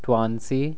2. Nasal Place Assimilation
All nasal consonants adopt the place of articulation of the following consonant.
Example: /twamsip/ --> [
twãnsi] 'tiny'